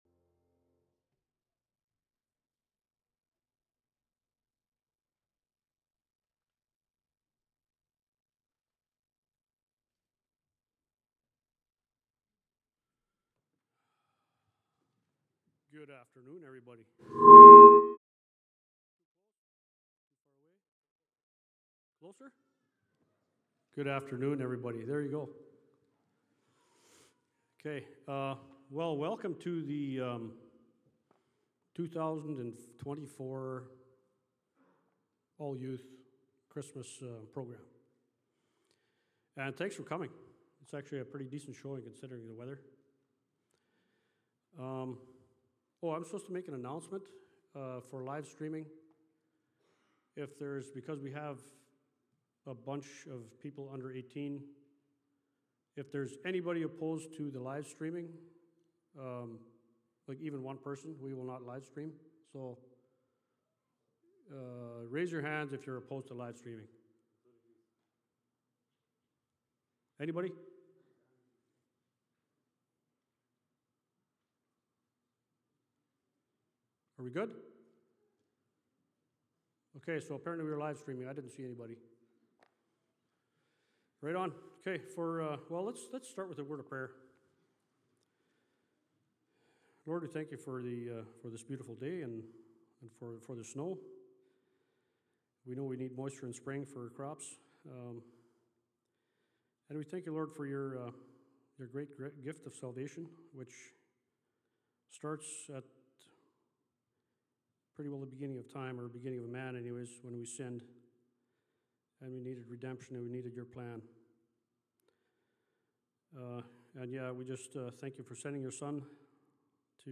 All Youth Christmas Program